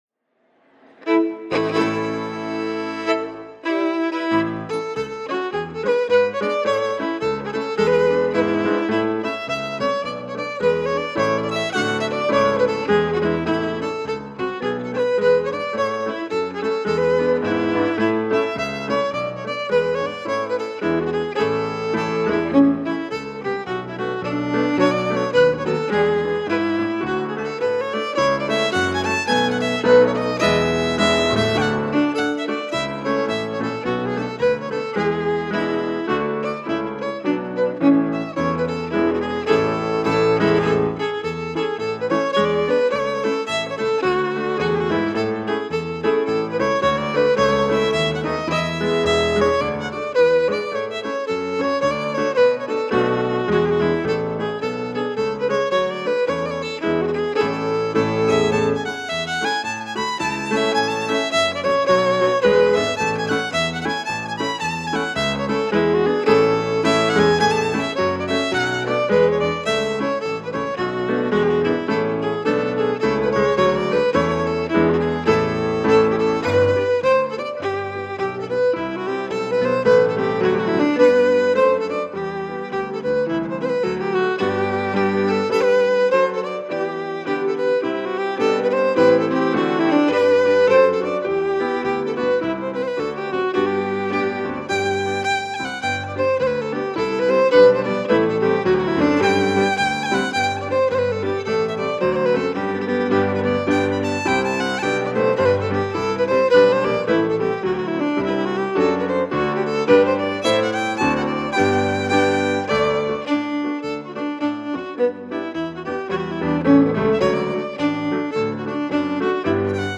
jig